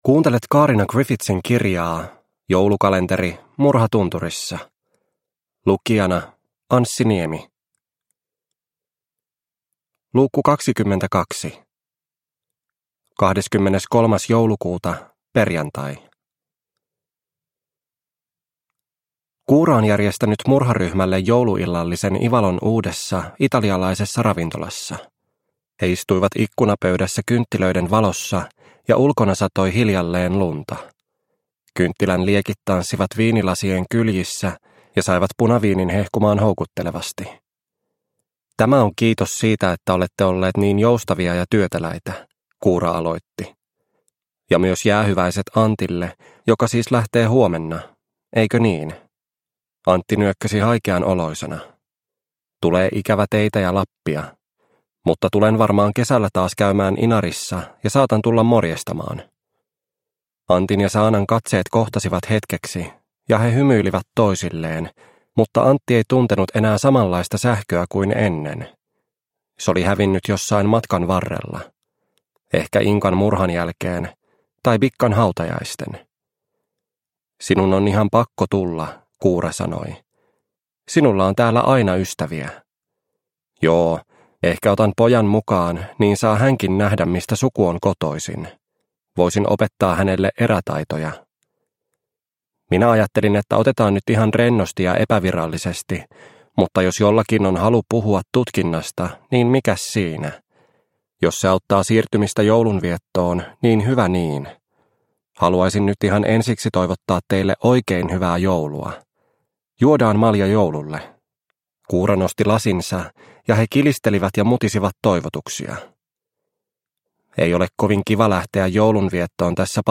Murha tunturissa - Osa 22 – Ljudbok – Laddas ner